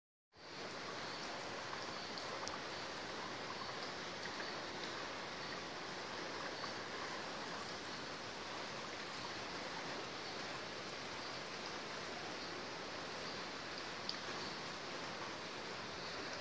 描述：在哥伦比亚农村记录的暴雨。
Tag: 现场记录 天气